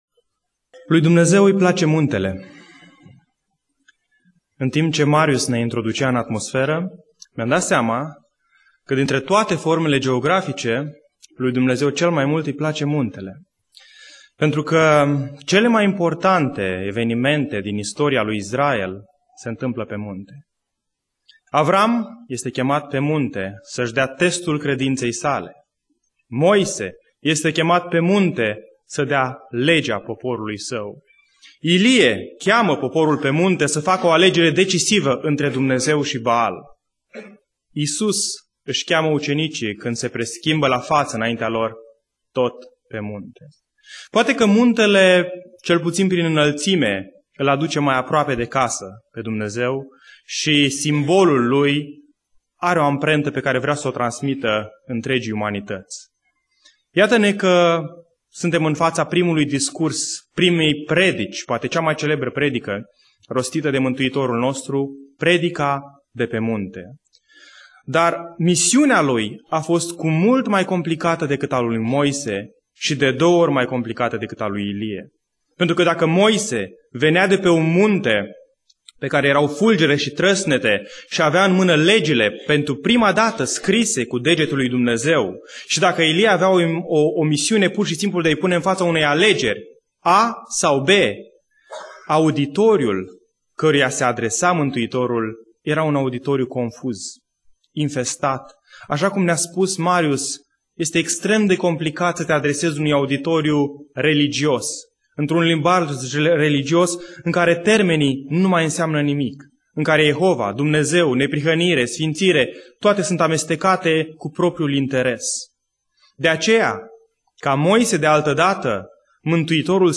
Predica Aplicatie - Matei 5